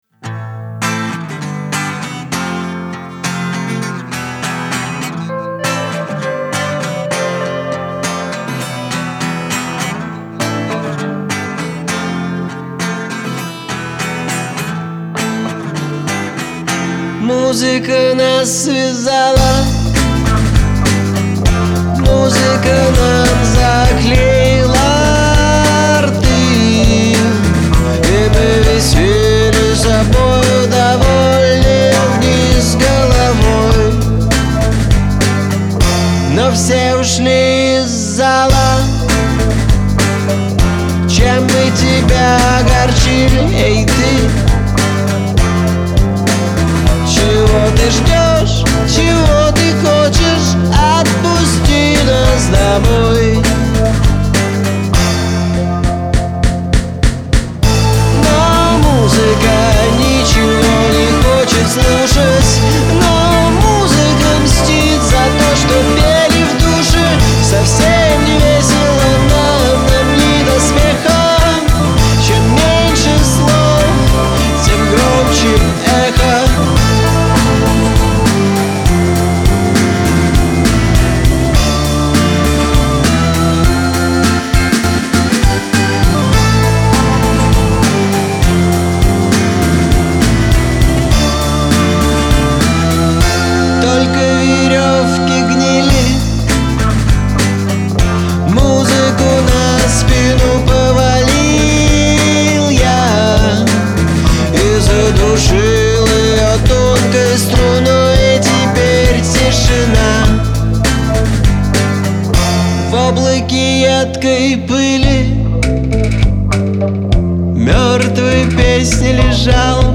Русские песни